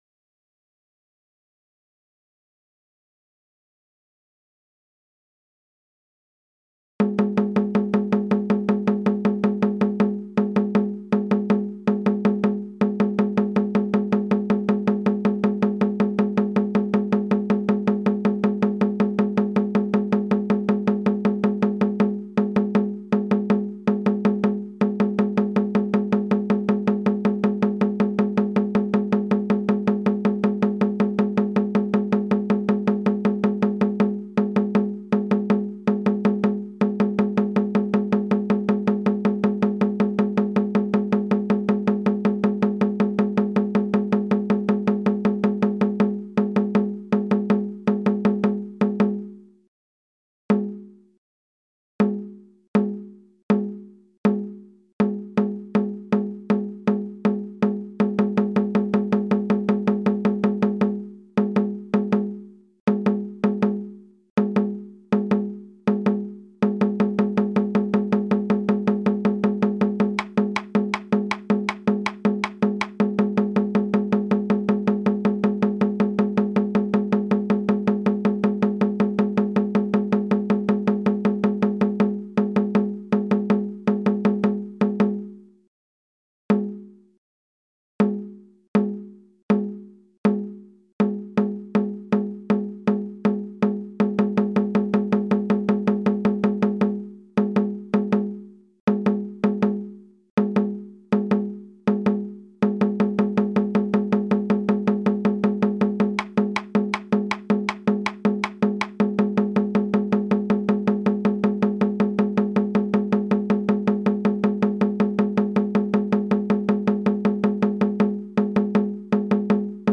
hajime-metronome+shime+zwei-80bpm-1000ms.ogg (1.7M - updated 1 year, 2 months ago)